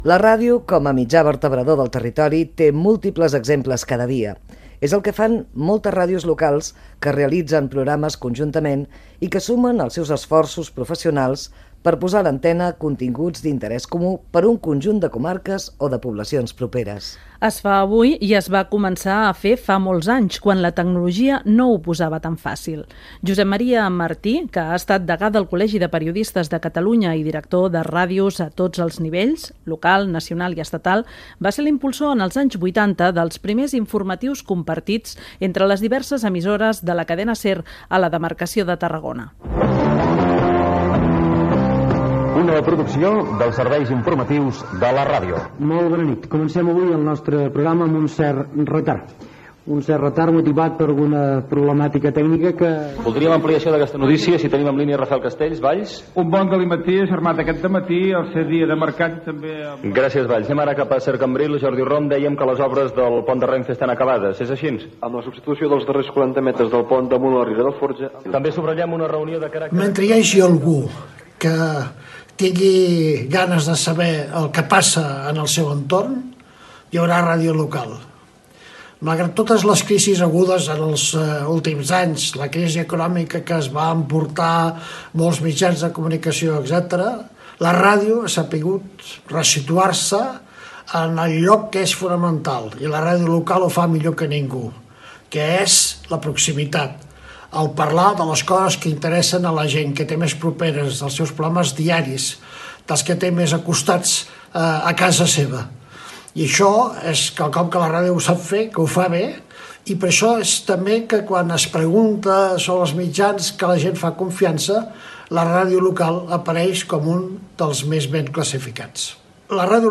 Especial emès amb motiu del Dia Mundial de la Ràdio 2020.
Divulgació